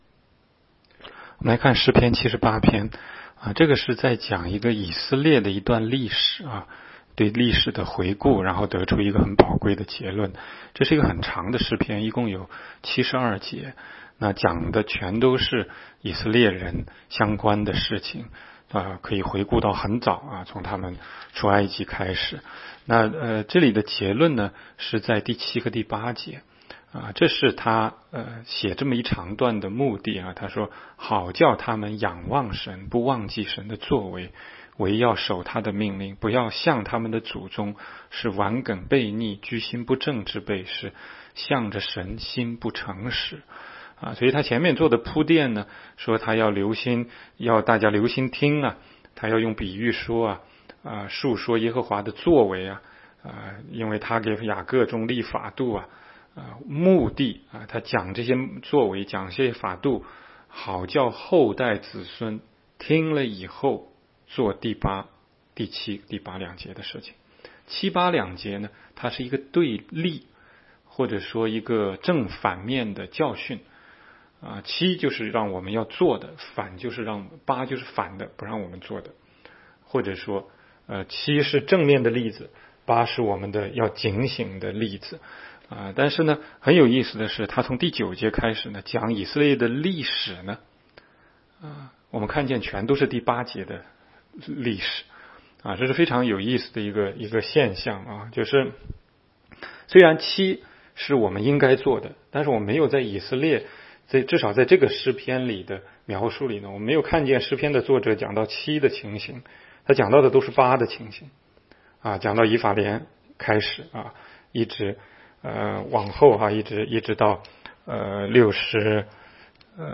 16街讲道录音 - 每日读经-《诗篇》78章